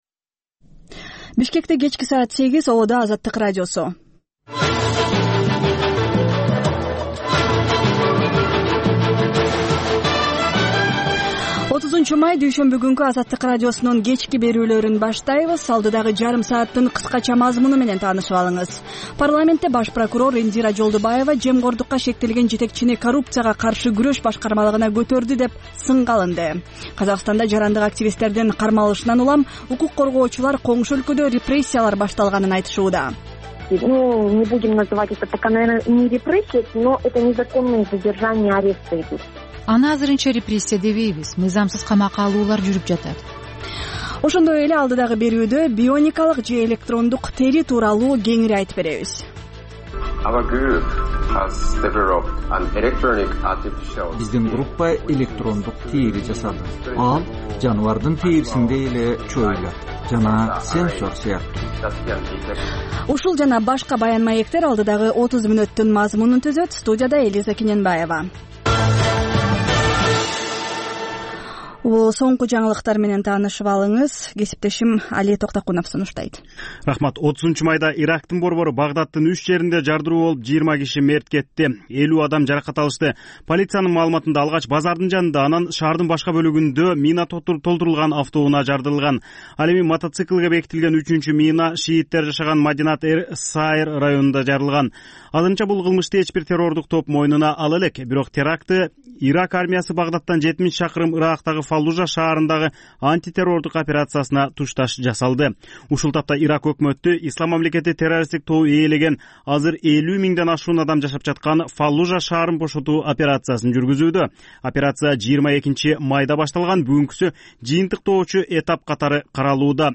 "Азаттык үналгысынын" бул кечки бир сааттык берүүсү ар күнү Бишкек убакыты боюнча саат 20:00дан 21:00гө чейин обого түз чыгат.